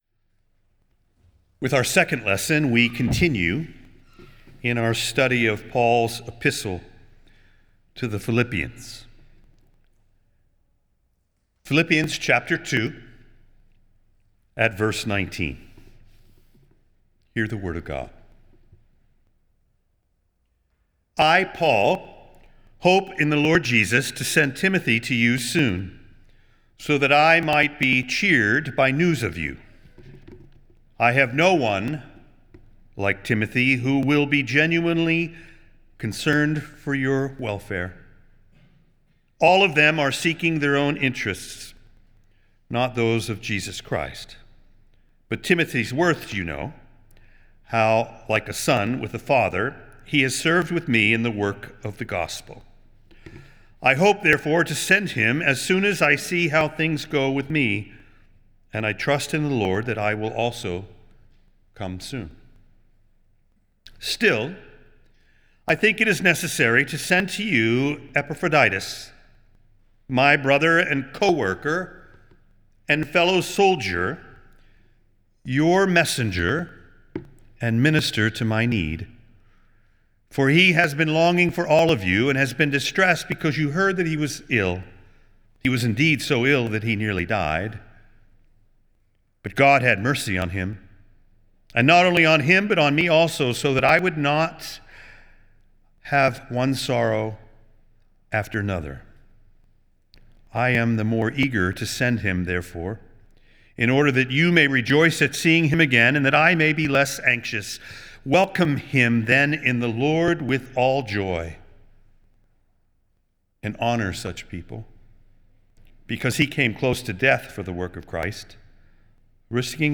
Nassau Presbyterian Church Sermon Journal Co-Workers Mar 08 2026 | 00:19:57 1x 00:00 / 00:19:57 Subscribe Share Apple Podcasts Spotify Amazon Music Overcast RSS Feed Share Link Embed